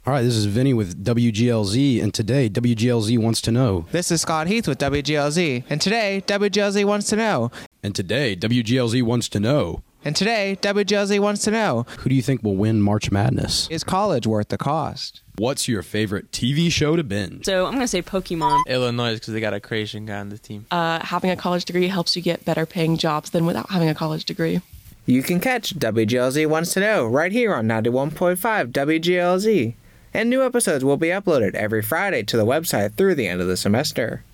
WGLZ Wants to Know – Audio Promo